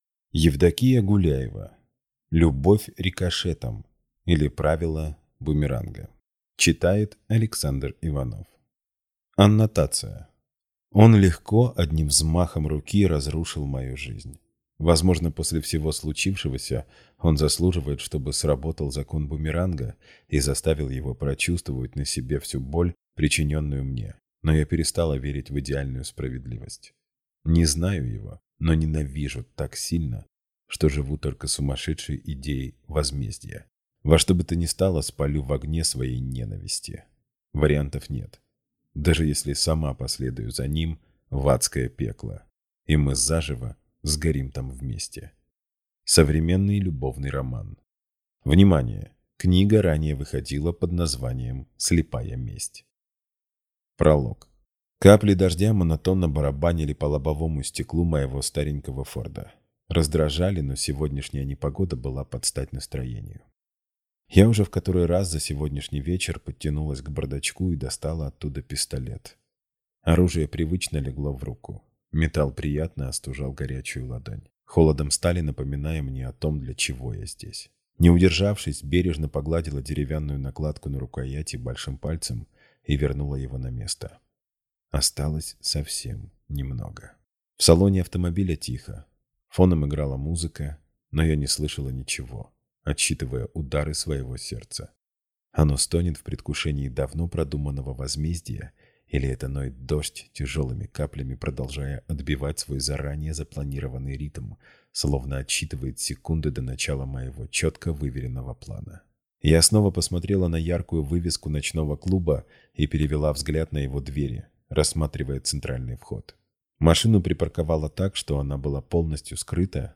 Аудиокнига Любовь рикошетом, или Правило бумеранга | Библиотека аудиокниг